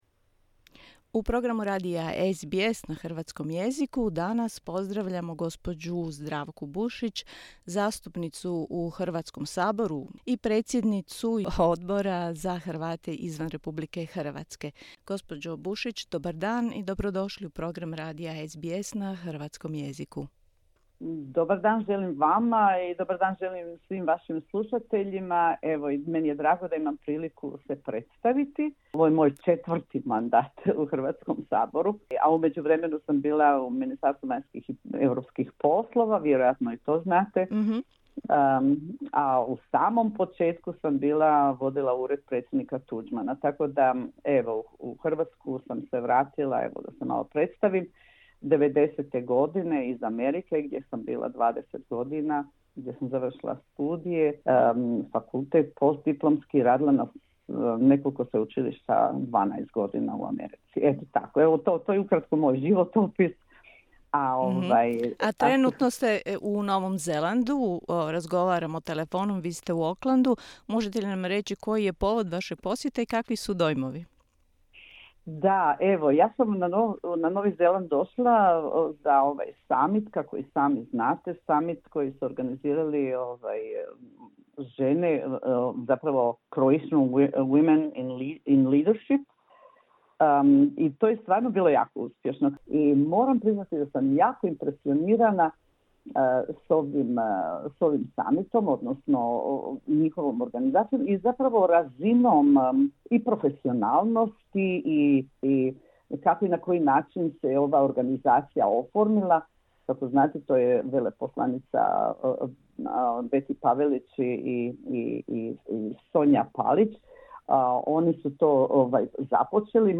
Zdravka Bušić govori na samitu organizacije Aus-Nz Croatian Women in Leadership, Auckland, Novi Zeland